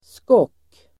Uttal: [skåk:]